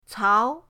cao2.mp3